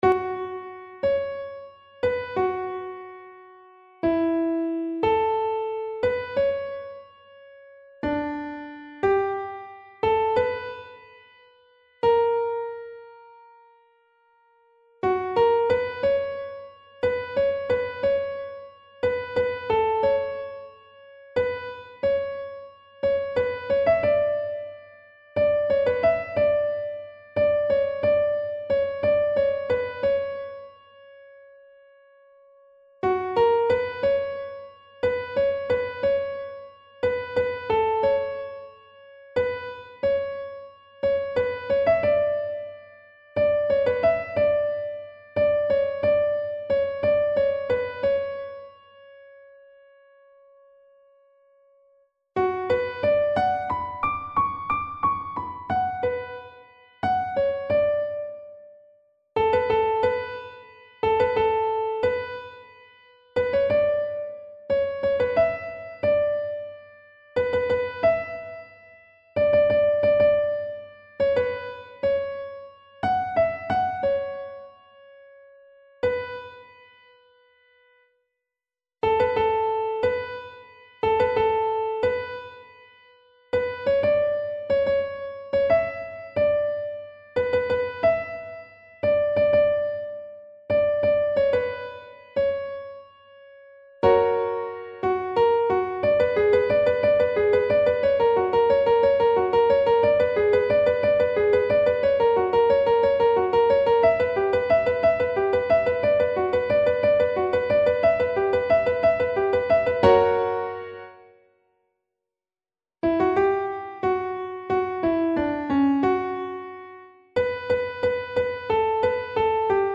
این محصول شامل دو نسخه (گام اصلی+گام ساده) می باشد